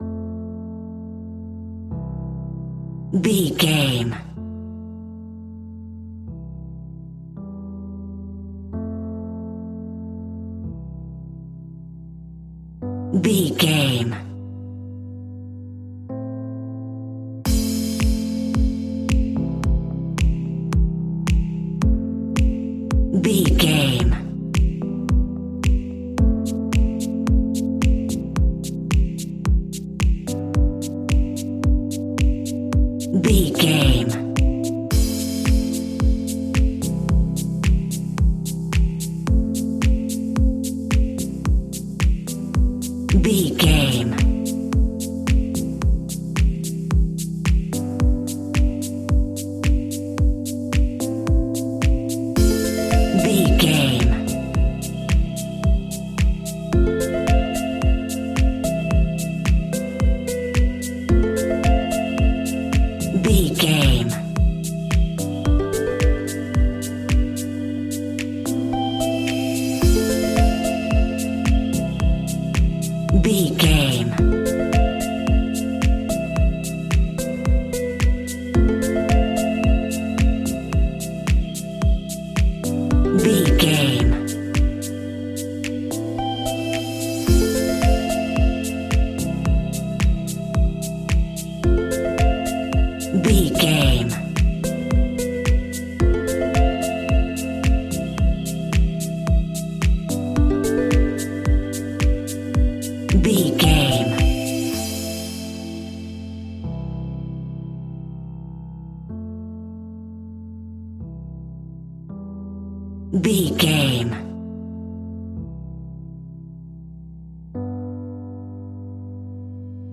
Aeolian/Minor
groovy
peaceful
meditative
smooth
drum machine
synthesiser
house
electro house
funky house
instrumentals
synth leads
synth bass